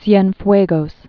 (syĕn-fwāgōs)